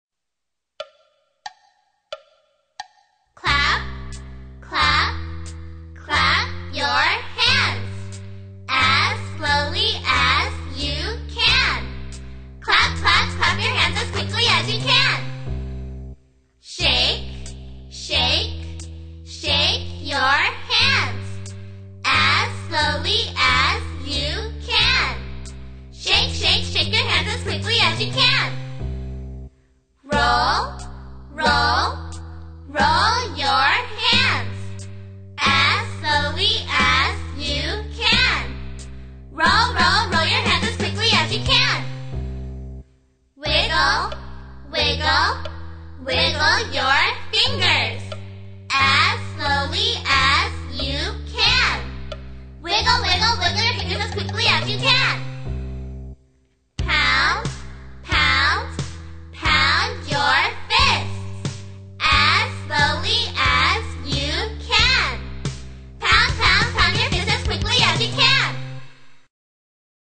在线英语听力室英语儿歌274首 第22期:Clap your hands的听力文件下载,收录了274首发音地道纯正，音乐节奏活泼动人的英文儿歌，从小培养对英语的爱好，为以后萌娃学习更多的英语知识，打下坚实的基础。